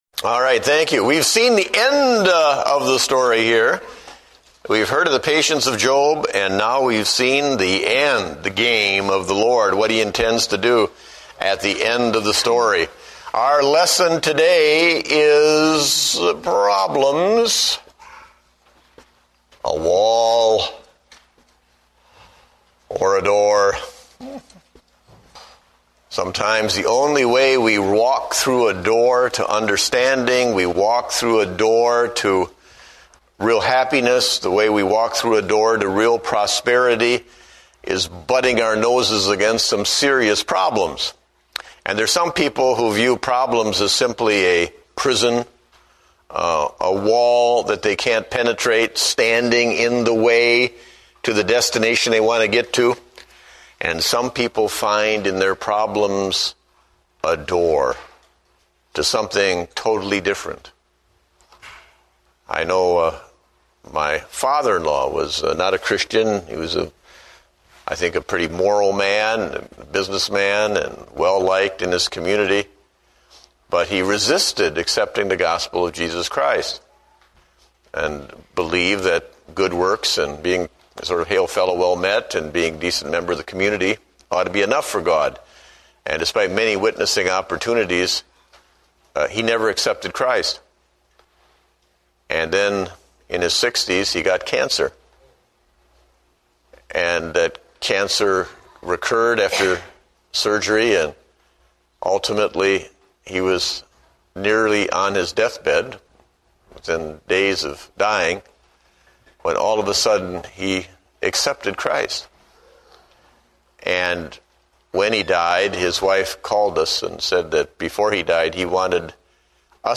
Date: October 5, 2008 (Adult Sunday School)